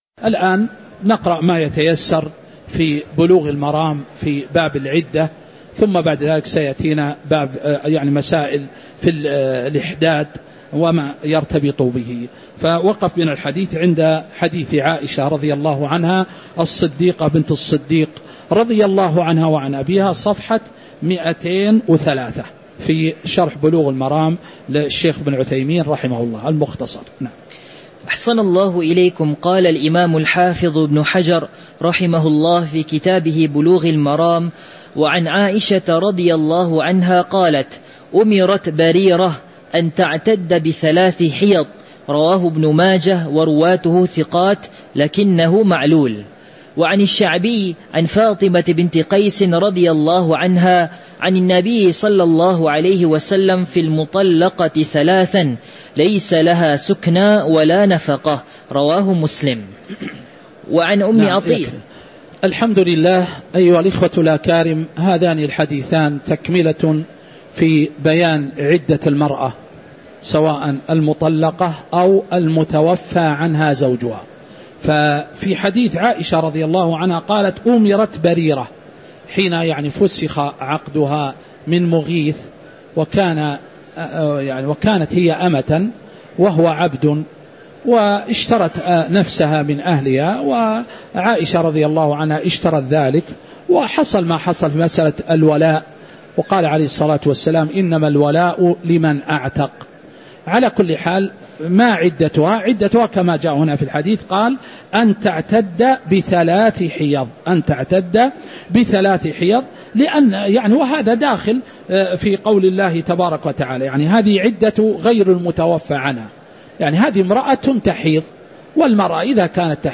تاريخ النشر ١٣ ذو القعدة ١٤٤٦ هـ المكان: المسجد النبوي الشيخ